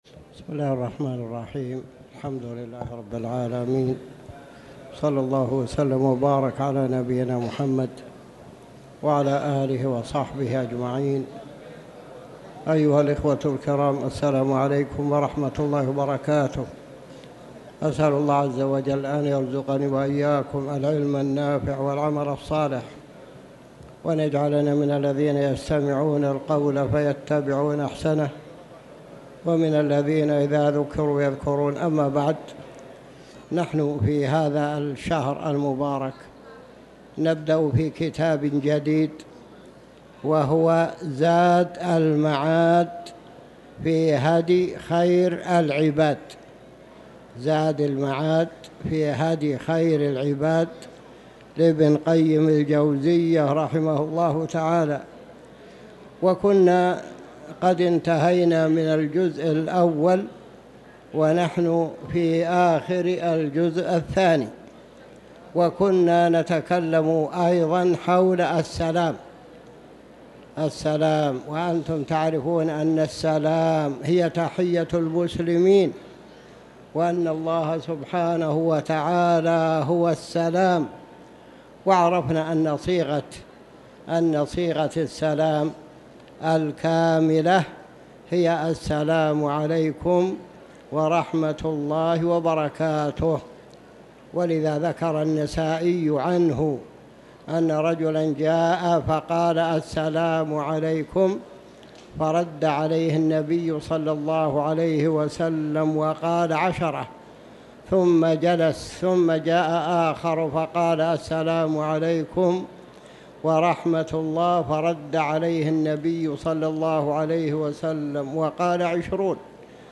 تاريخ النشر ١٦ ذو الحجة ١٤٤٠ هـ المكان: المسجد الحرام الشيخ